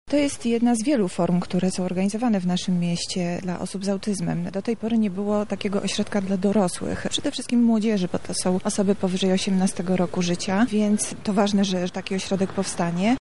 – W Lublinie mamy już wiele ośrodków wspierających osoby z autyzmem, ale brakowało właśnie takiego – stwierdza Monika Lipińska, zastępca prezydenta